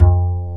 Index of /90_sSampleCDs/Roland L-CDX-01/BS _Jazz Bass/BS _Ac.Fretless